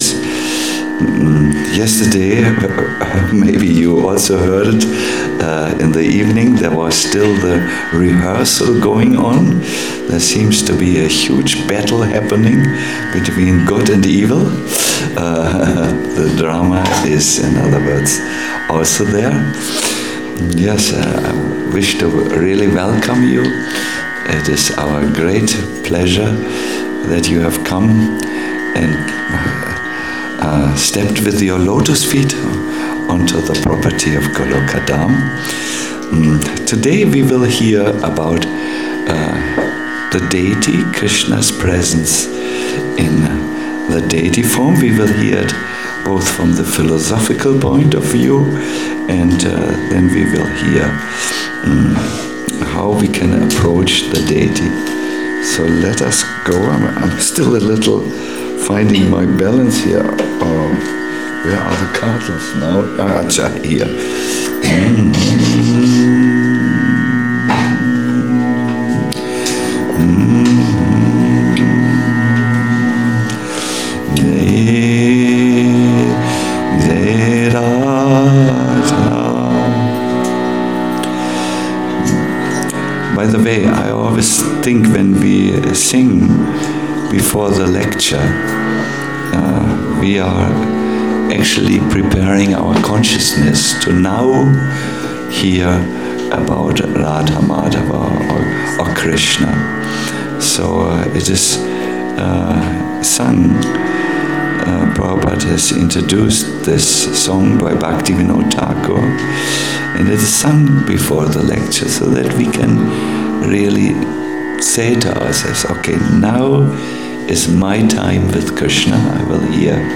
SB 3.25.35 Krishnas Full Presence in the Deity - a lecture